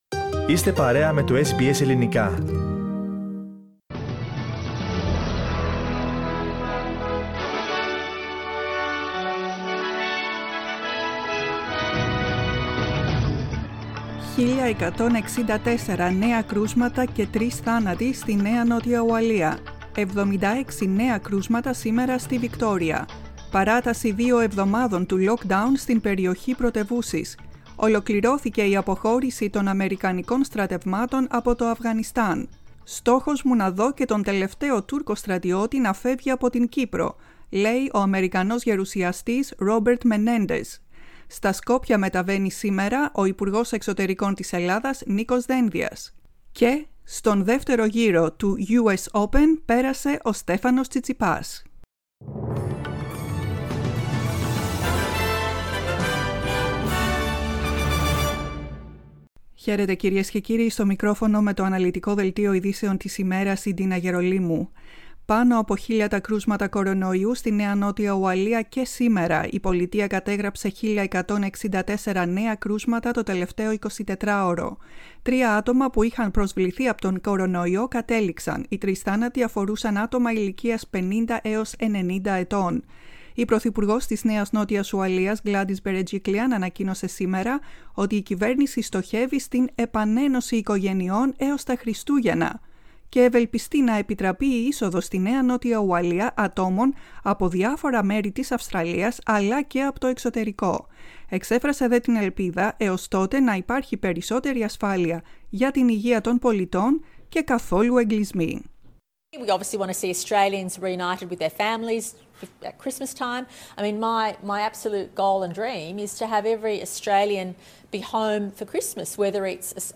News bulletin, 31.08.21